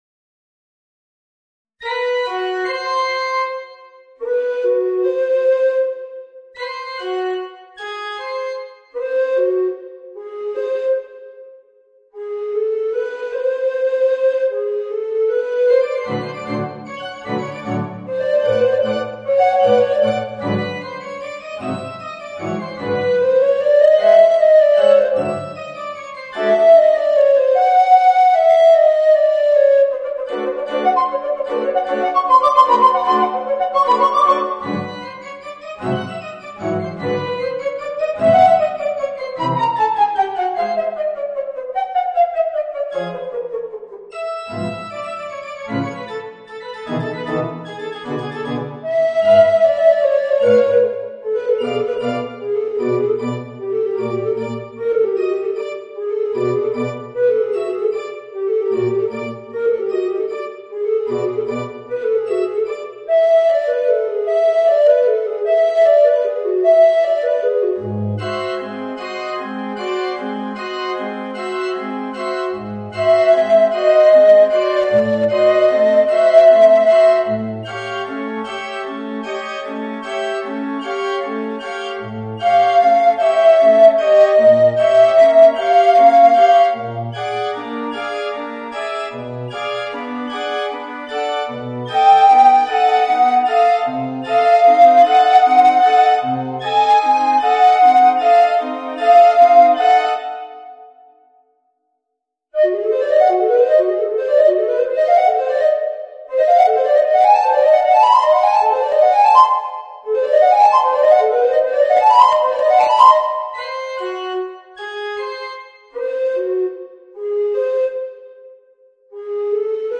Voicing: Alto Recorder and Piano